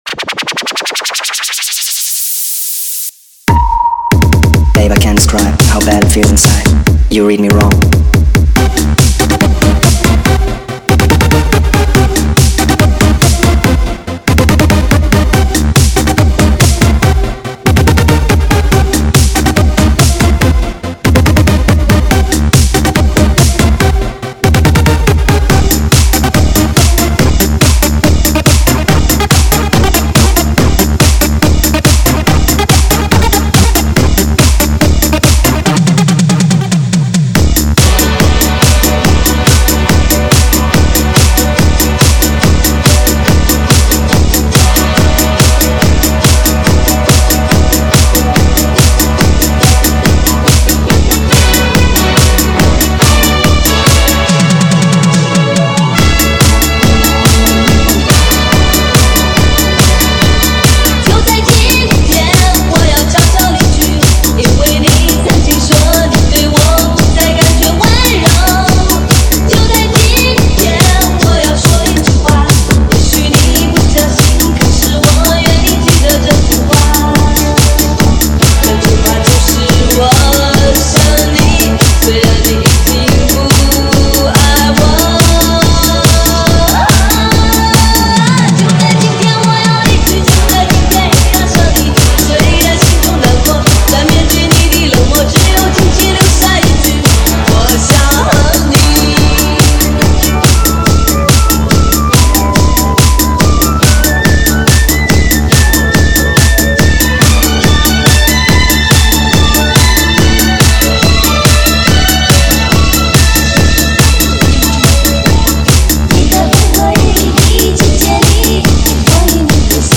联唱只是我改成DJ版，希望你喜欢。